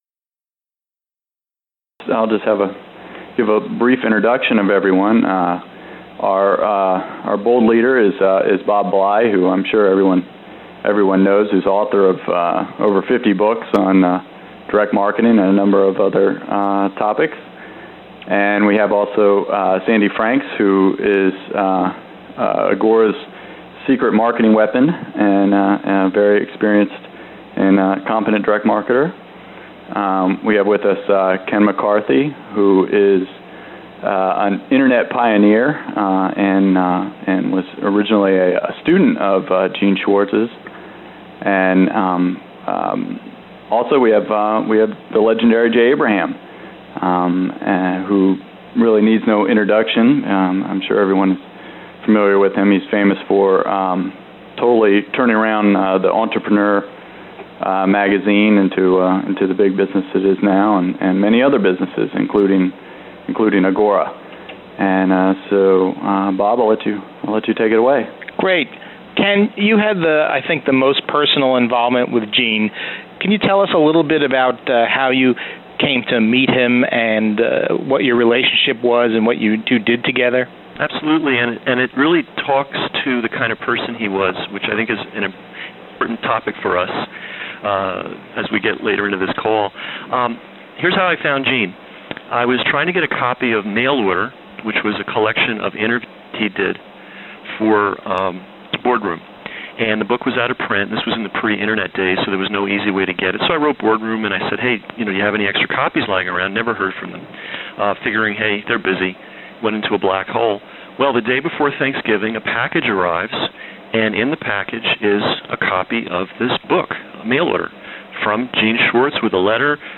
Here’s the lead off lesson featuring Jay Abraham and yours truly.